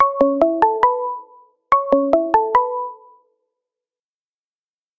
알림음(효과음) + 벨소리
알림음 8_땅동당동동.ogg